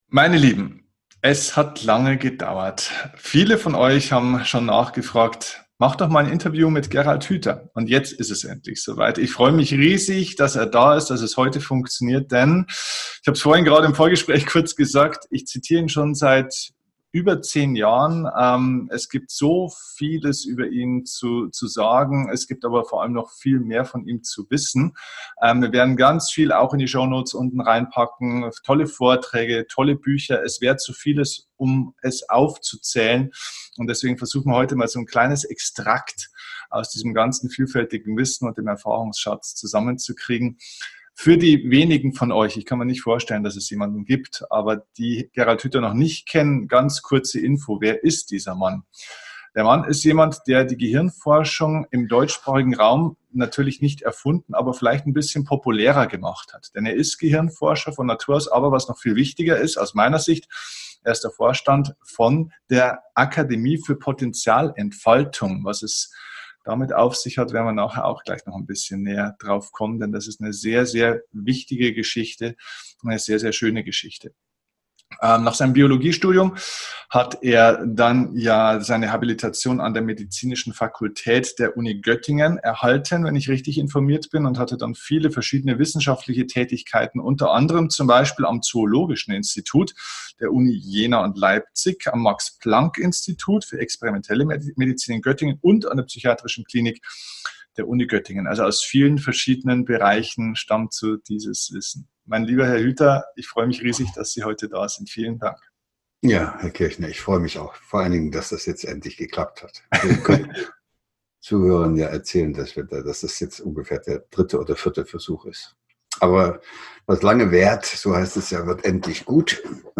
#250 Worauf es in der Welt von morgen ankommt - Interview mit Gehirnforscher Gerald Hüther | Hirnforschung | Zukunft | Veränderung ~ DIE KUNST ZU LEBEN - Dein Podcast für Lebensglück, moderne Spiritualität, emotionale Freiheit und berufliche Erfüllung Podcast